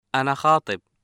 [ʔana xaaTeb]